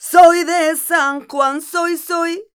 46a08voc-c#.wav